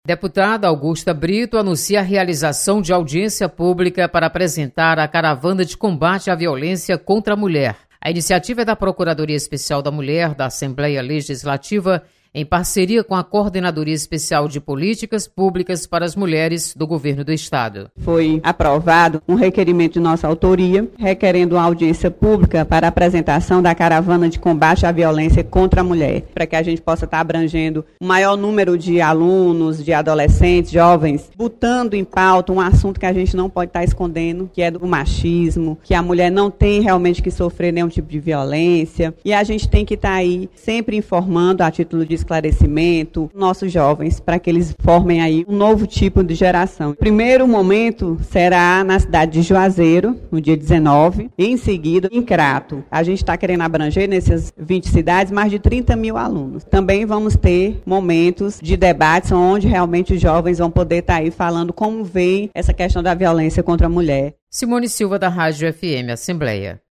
Deputada Augusta Brito vai comandar audiência pública para debater sobre combate à violência contra a mulher. Repórter